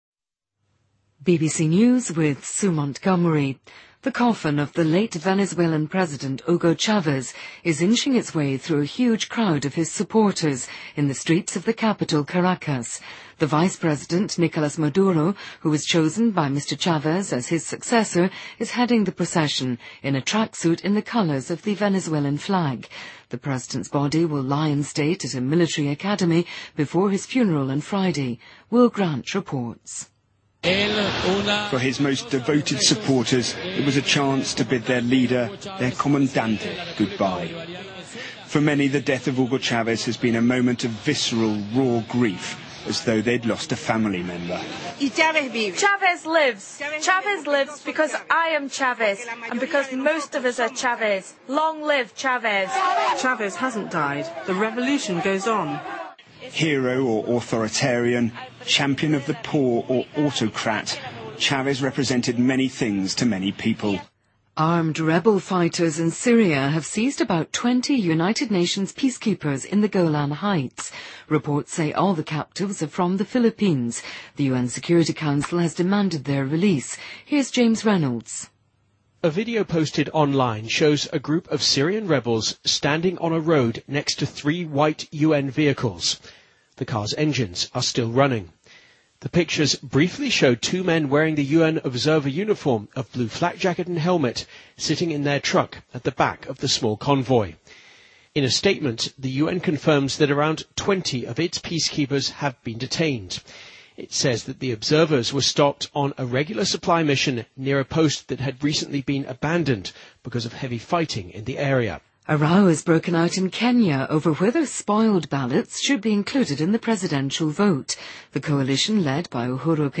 BBC news,2013-03-07